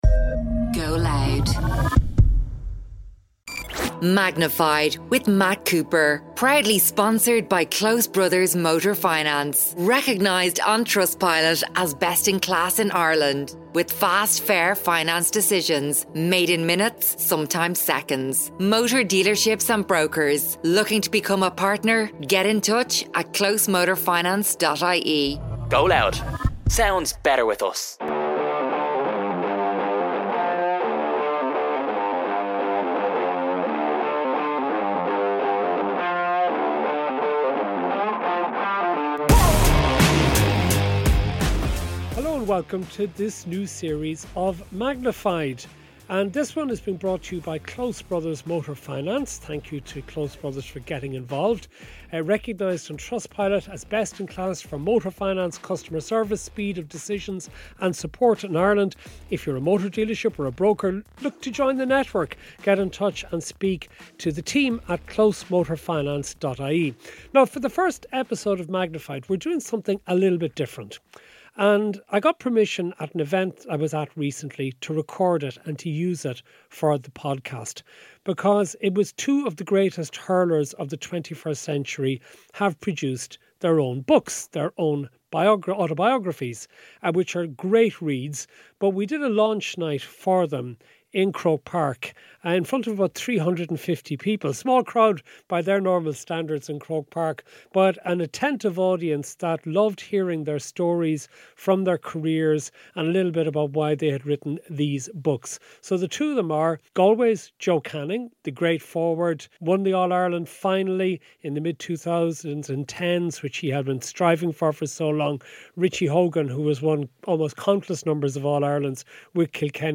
A podcast hosted by Matt Cooper featuring intimate, in-depth and insightful interviews with guests from across the worlds of entertainment, business, sports, politics and more.
It’s a Matt Cooper interview, magnified.